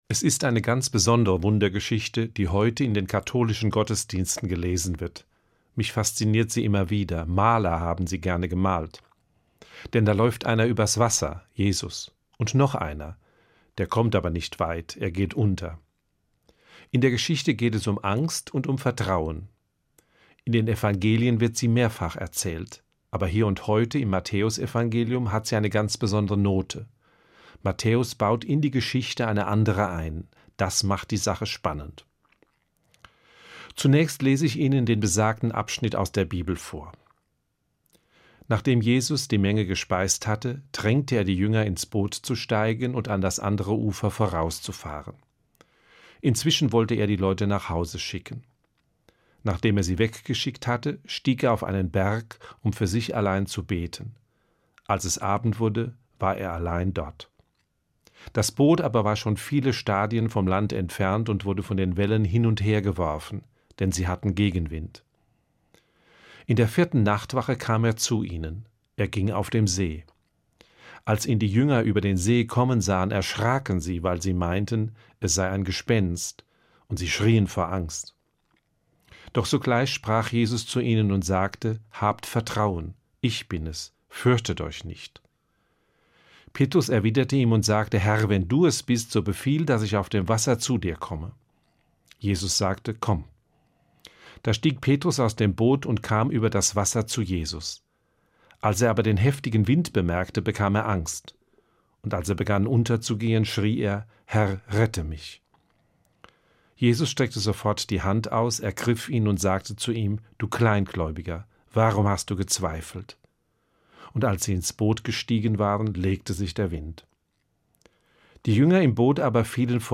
Zunächst lese ich Ihnen den besagten Abschnitt aus der Bibel vor: